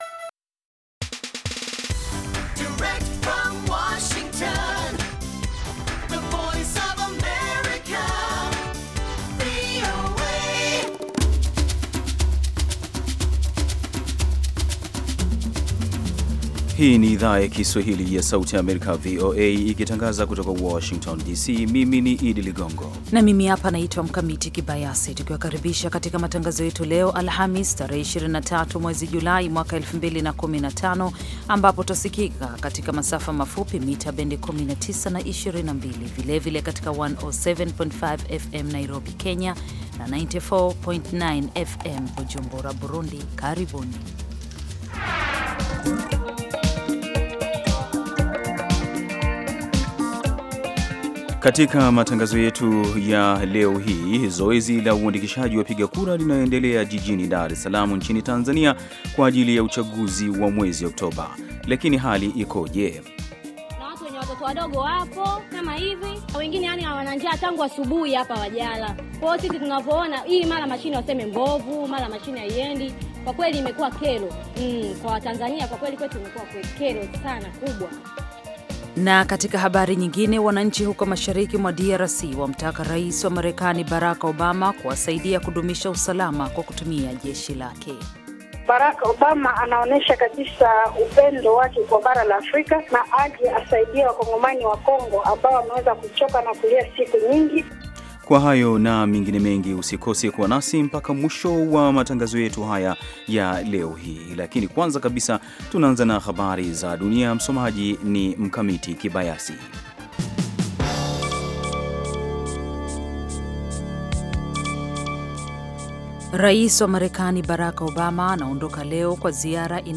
Matangazo ya saa nzima kuhusu habari za kutwa, ikiwa ni pamoja ripoti kutoka kwa waandishi wetu sehemu mbali mbali duniani na kote Afrika Mashariki na Kati, na vile vile vipindi na makala maalum kuhusu afya, wanawake, jamii na maendeleo.